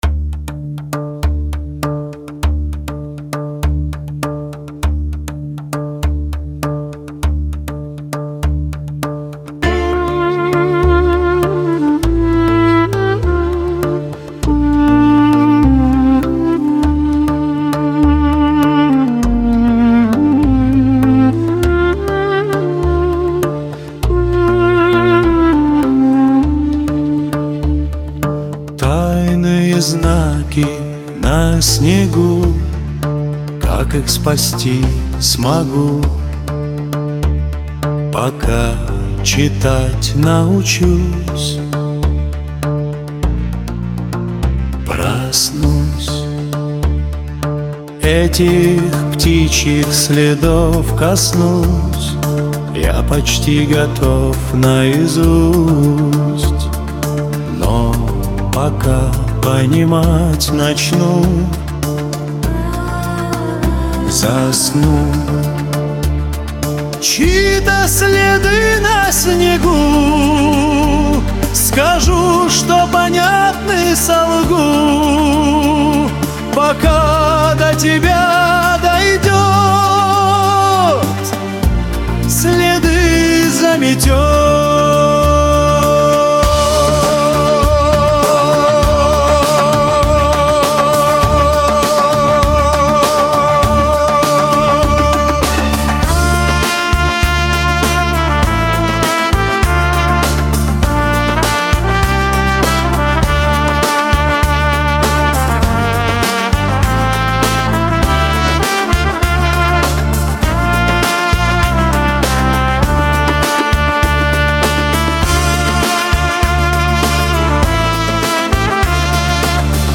• Жанр: AI Generated
Мелодия полностью сохранена.